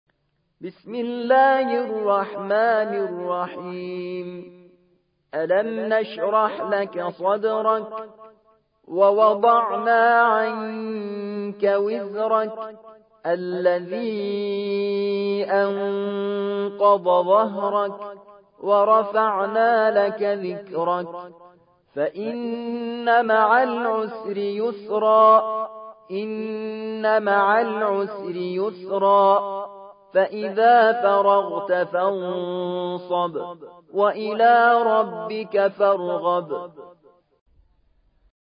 94. سورة الشرح / القارئ